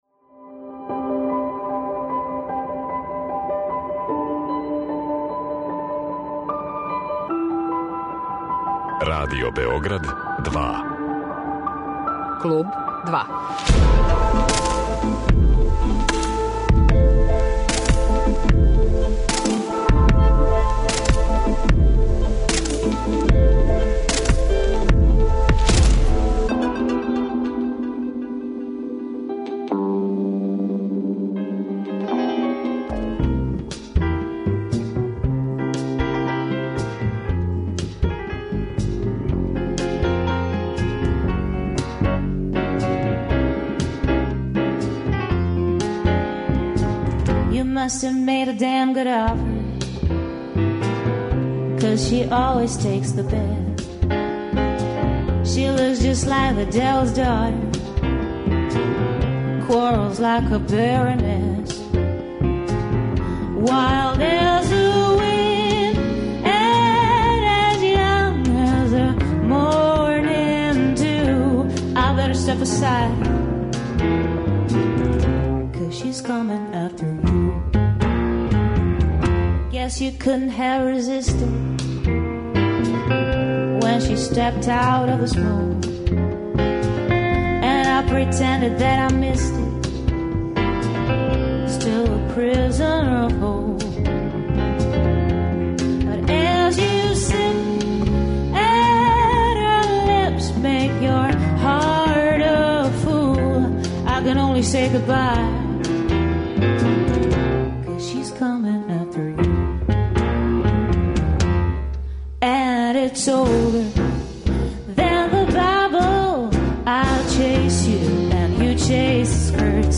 Овог петка, у Клубу 2, угостићемо изузетну блуз, џез кантауторку.